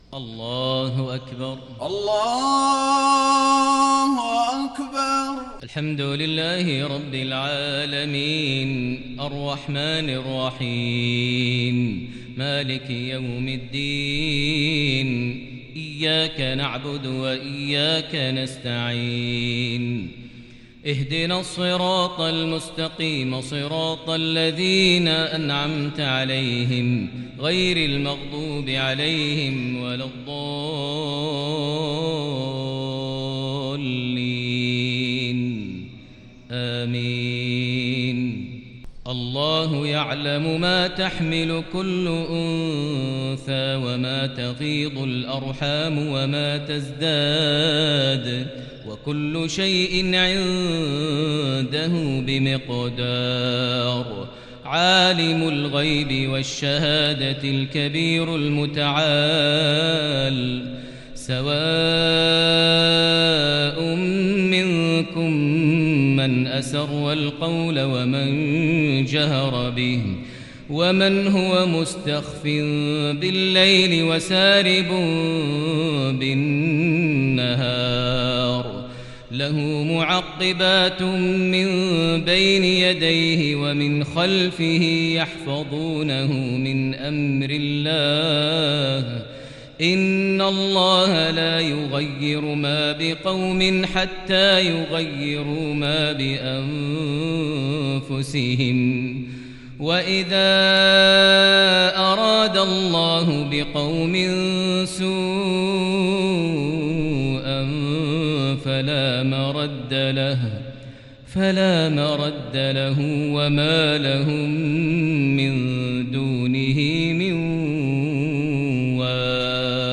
صلاة المغرب للشيخ ماهر المعيقلي 24 محرم 1442 هـ
تِلَاوَات الْحَرَمَيْن .